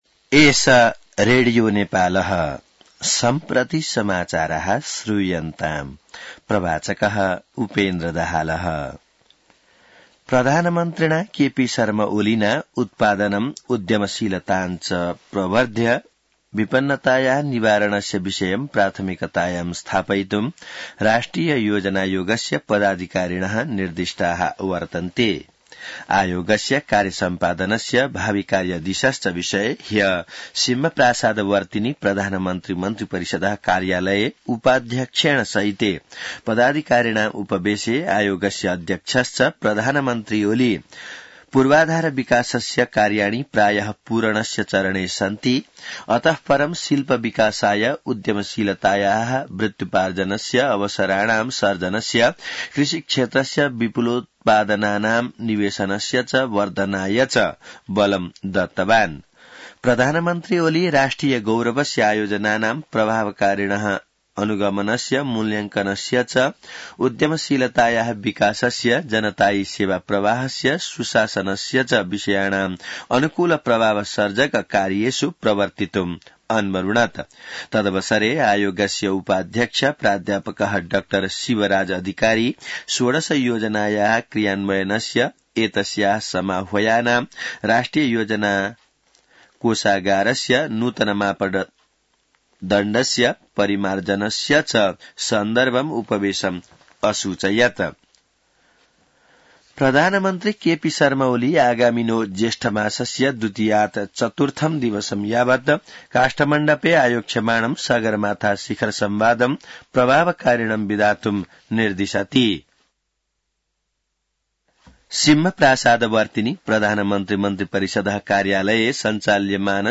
संस्कृत समाचार : २ चैत , २०८१